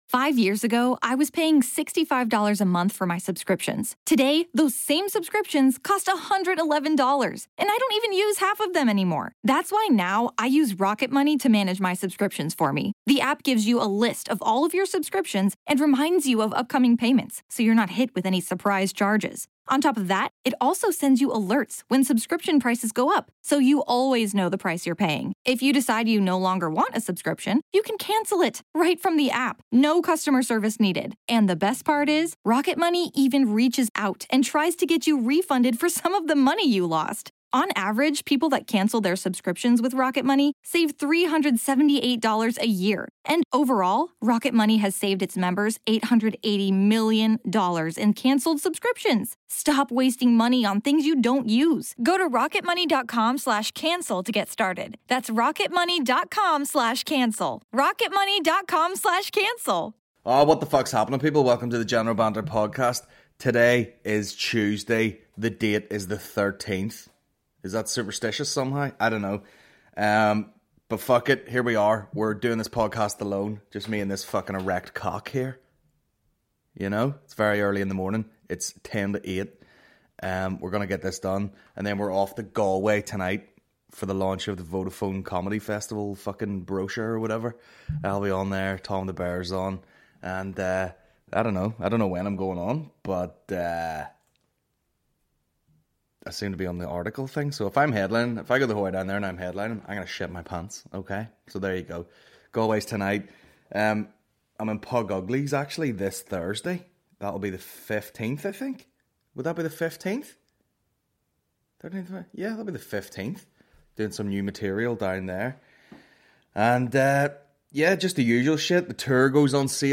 The General Banter Podcast is a Comedy podcast